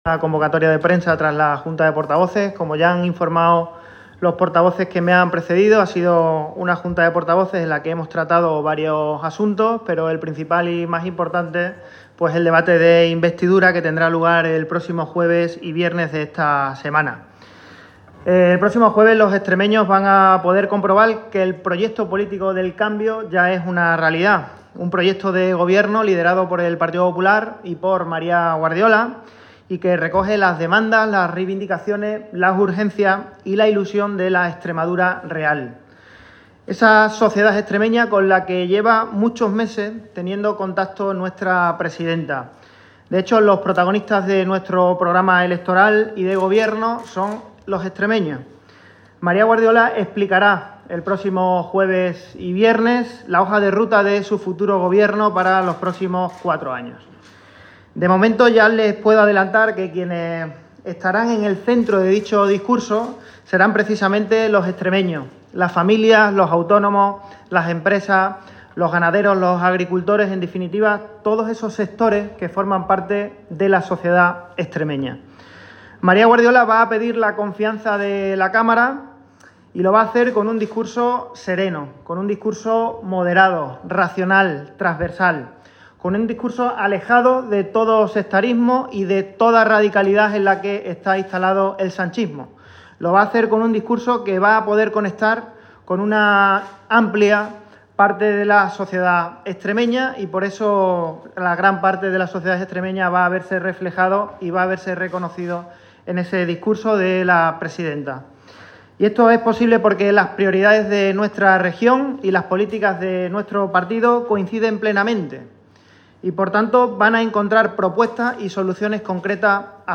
Abel Bautista, Portavoz GPP
Así lo ha indicado el portavoz del grupo parlamentario Popular en la Asamblea de Extremadura, Abel Bautista, después de la reunión de la Junta de Portavoces, previa a la celebración del debate de investidura que tendrá lugar los días 13 y 14 de julio.